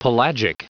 Prononciation du mot pelagic en anglais (fichier audio)
Prononciation du mot : pelagic